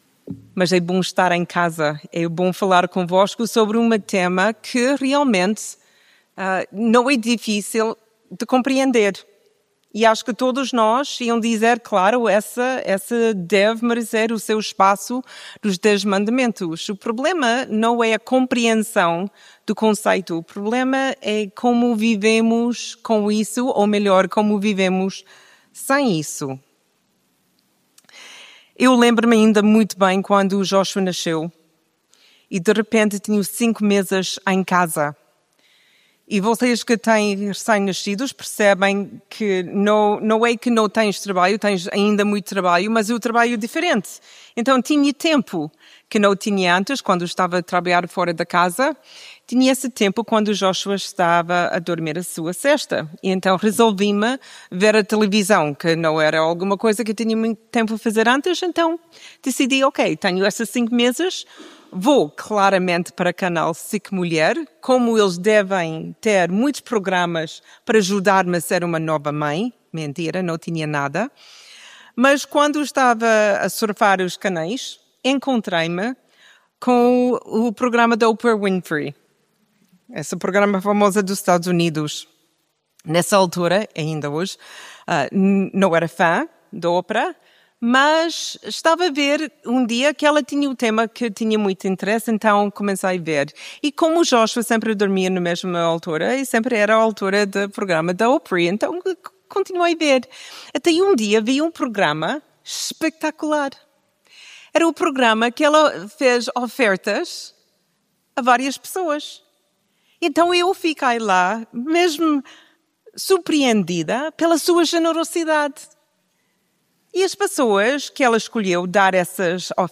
mensagem bíblica É perturbador quando vejo que outras pessoas têm o que eu sempre quis!